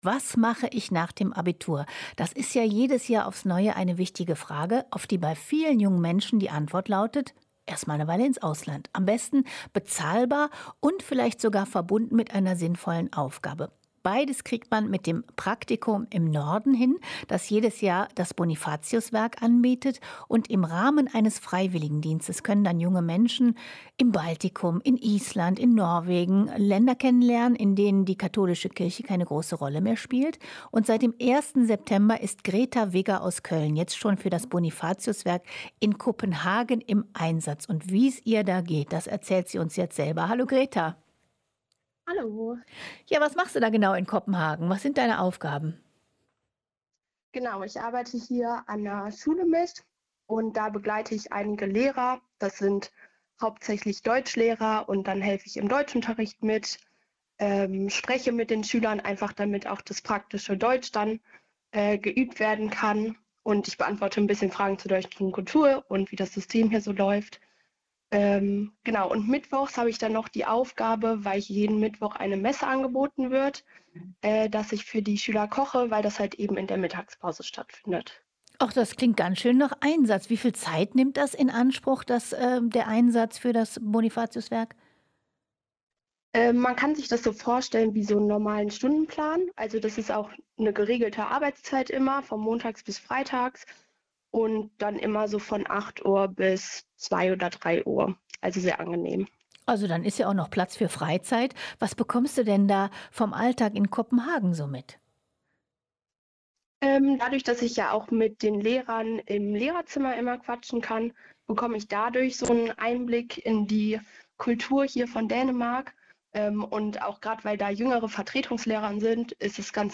Das "Praktikum im Norden" des Bonifatiuswerks ermöglicht jungen Menschen, in Island, Norwegen oder dem Baltikum Länder kennenzulernen, in denen katholische Christen eher in der Minderheit sind. Ein Interview